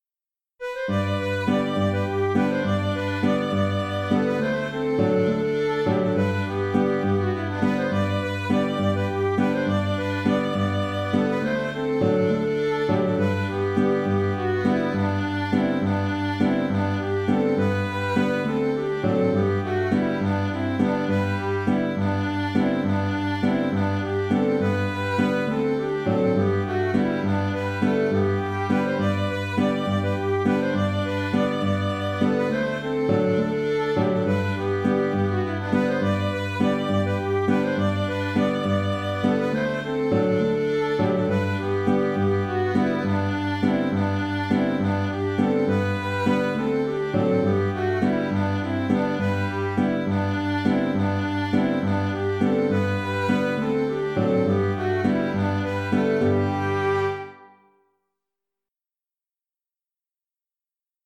Grandes Poteries 2 (Les) (Bourrée tournante ) - Musique folk